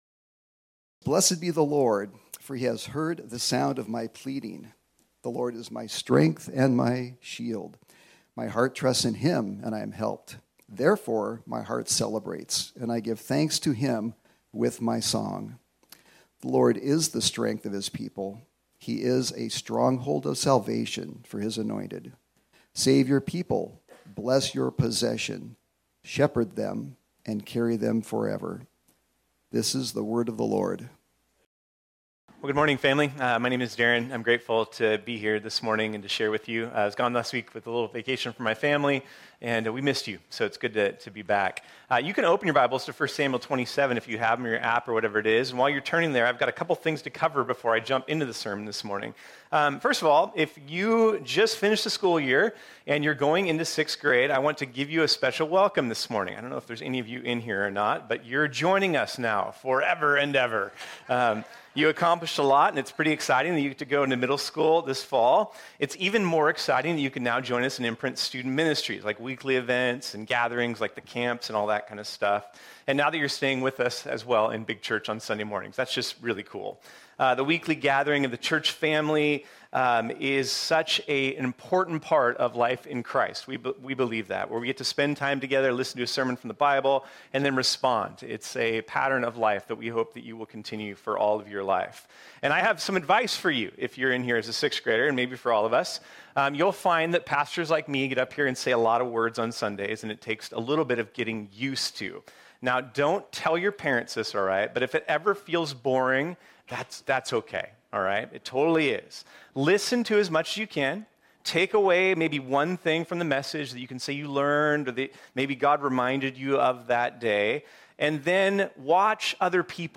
This sermon was originally preached on Sunday, March 12, 2023.